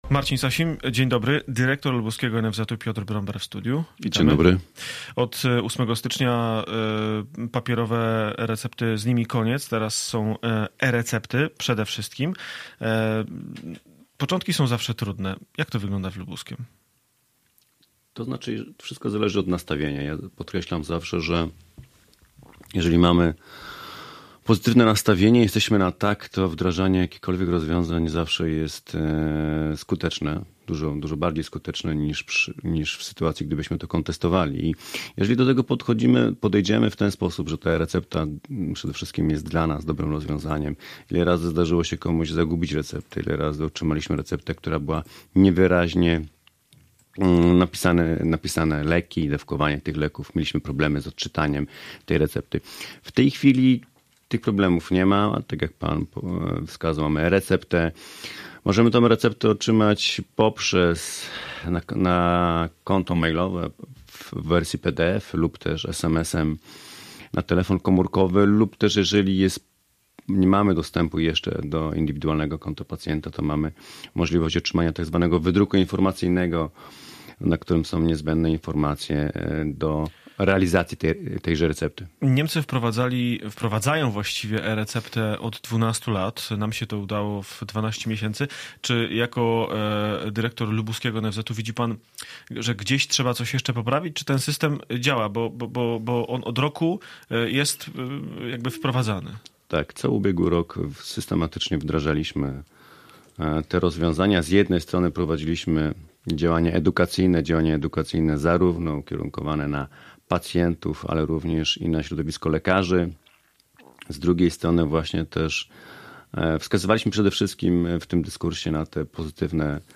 Z dyrektorem lubuskiego NFZ-u rozmawiał